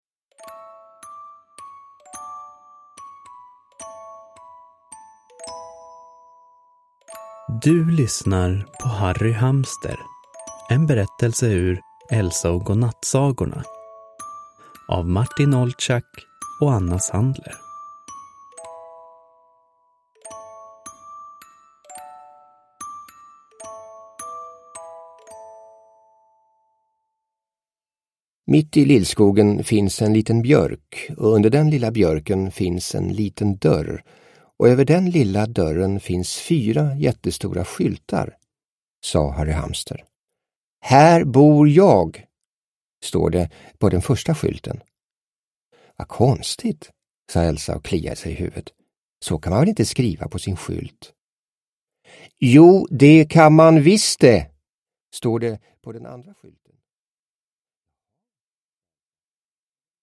Harry Hamster – Ljudbok – Laddas ner